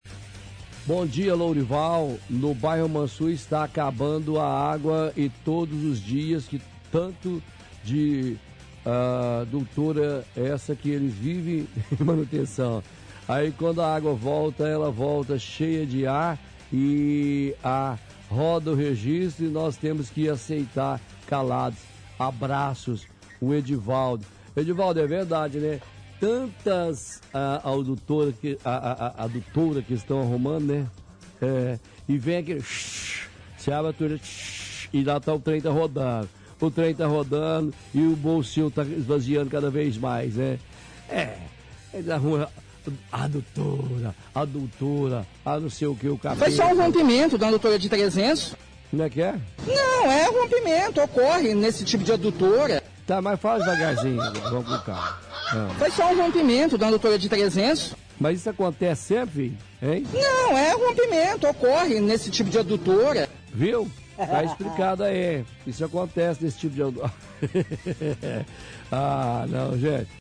– Piadas com áudio de funcionário da prefeitura falando de rompimento de adutora.